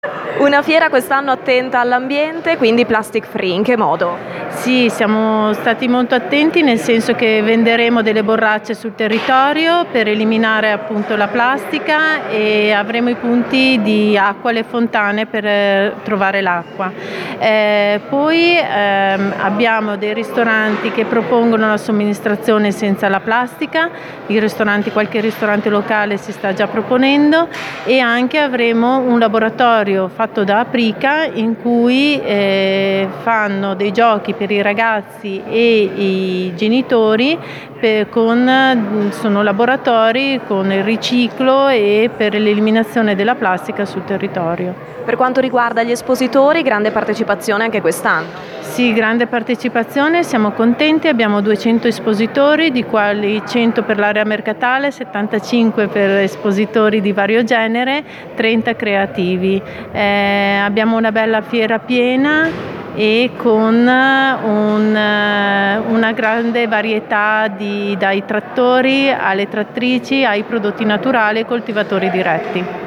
A questo scopo per tutta la durata della manifestazione saranno acquistabili delle borracce in alluminio da poter riempire gratuitamente nelle cinque fontane dislocate nel borgo, come confermato dall’assessore alle attività produttive del comune di Curtatone, Cinzia Cicola: